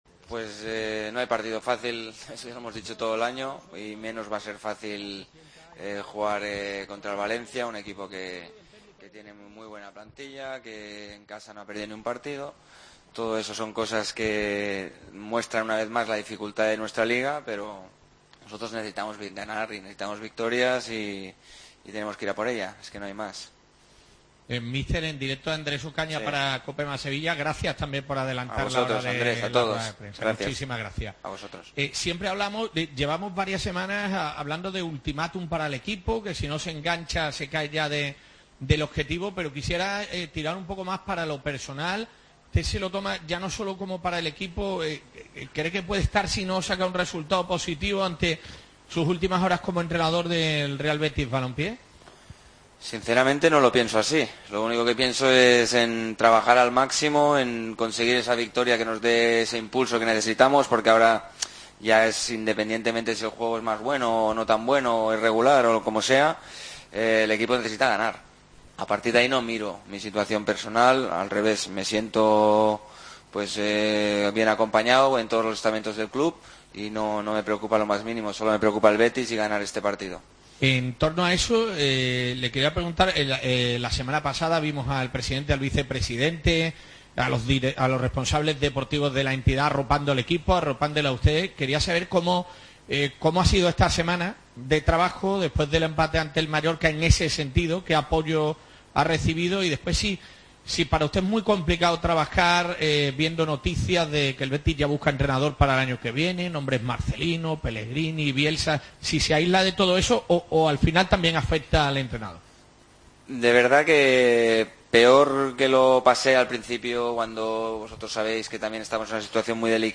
Rubi, en la comparecencia de prensa previa al Valencia-Betis